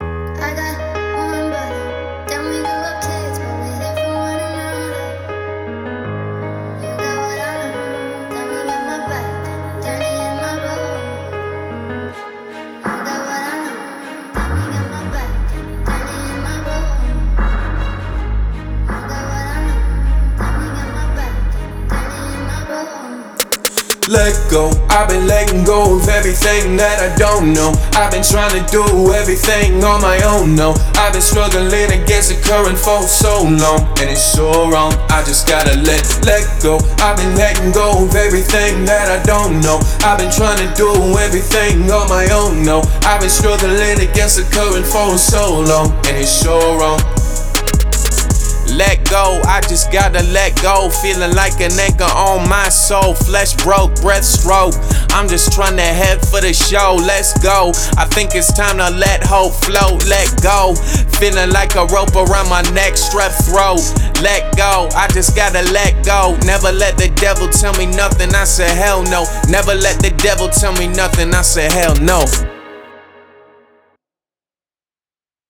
Mixing/Mastering
Let Go - Mastered.wav